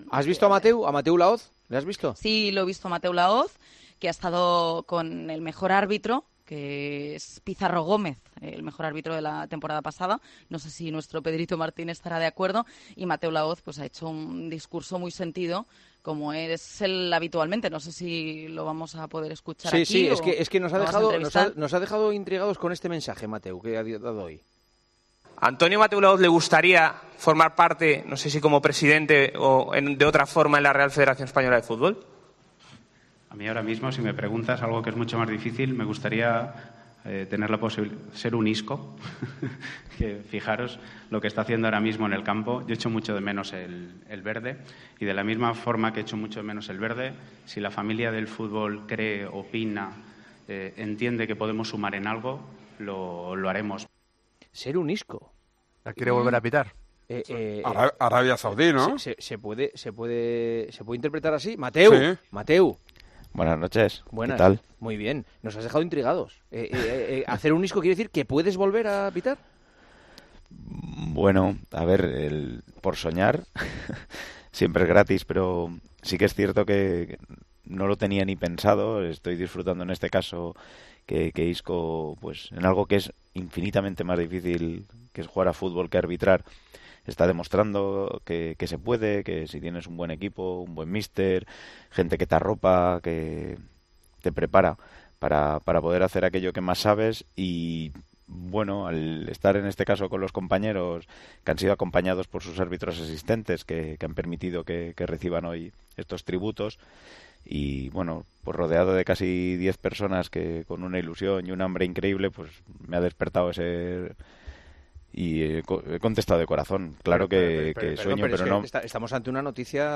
AUDIO: El ex árbitro le reconoció a Juanma Castaño en El Partidazo de COPE su deseo de volver a los terrenos de juego, después de haberse retirado a...